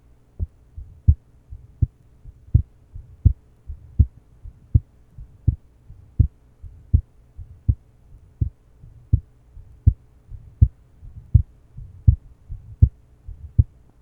Date 1971 Type Systolic Abnormality Pulmonary Stenosis 12 year old good innocent pulmonary flow murmur To listen, click on the link below.